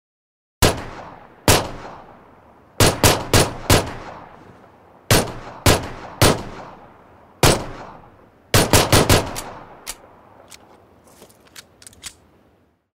Tiếng Súng Lục